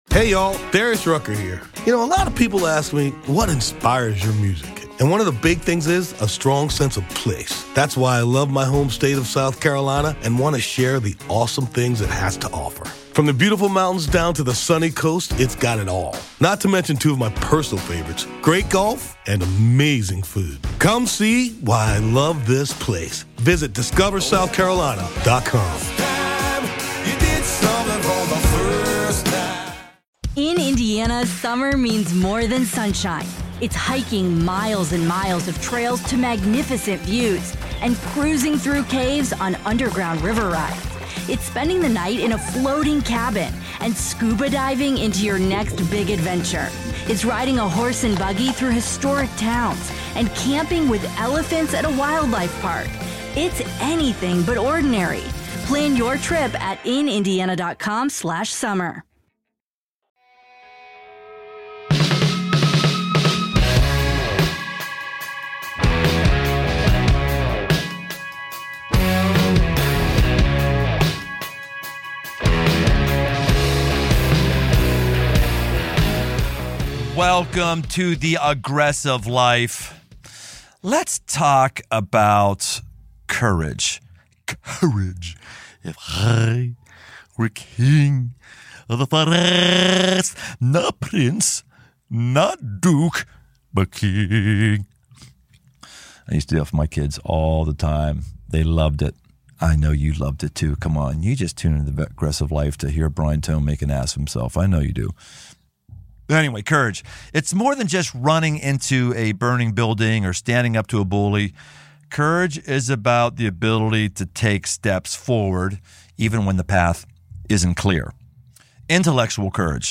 In this can’t-miss conversation, she shares what she’s learned about courage, calling, and grapevines.